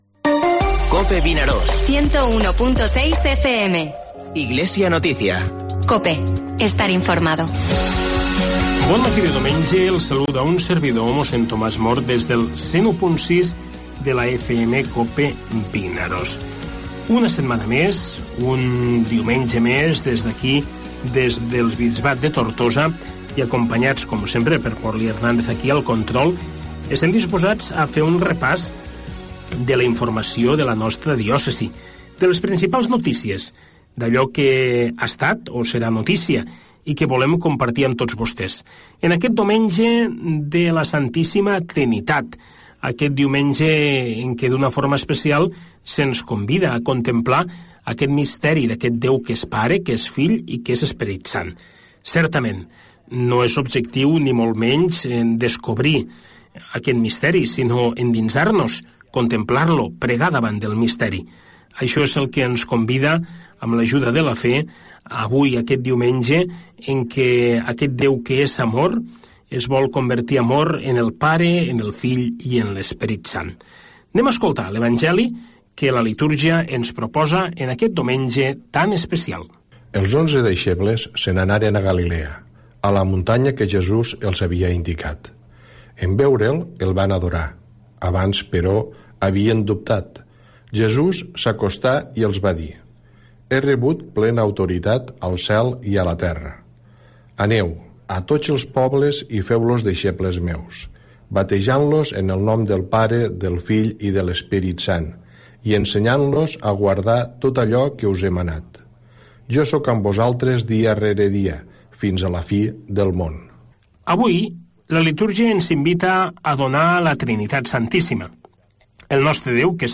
AUDIO: Espai informatiu del Bisbat de Tortosa, tots els diumenges de 9:45 a 10 hores.